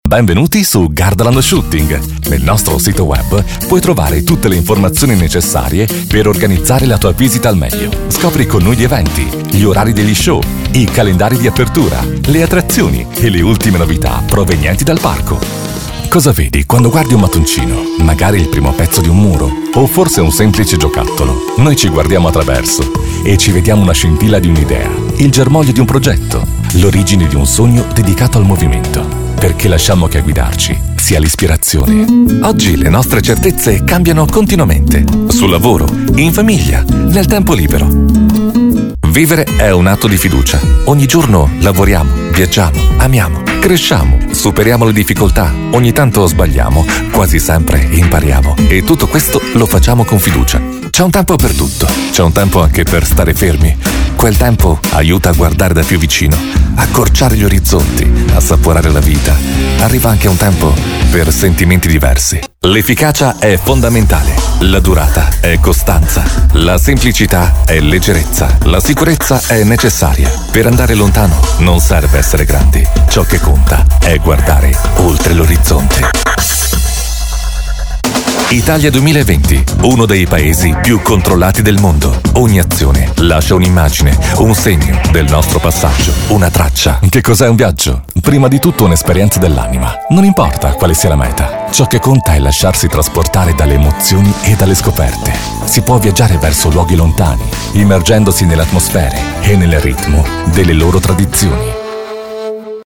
Demonstração Comercial
Narração
Minha voz é jovem e versátil. Quente para narrações, dinâmica para comerciais, suave e profissional para apresentações. MEU EQUIPAMENTO DE ESTÚDIO DE GRAVAÇÃO: Neumann TLM 103, Apollo Twin, Pro Tools, Plugin Waves e Uad.
EsquentarEleganteDinâmicoVersátilFrescoSuaveJovemNeutro